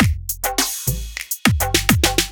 103 BPM Beat Loops Download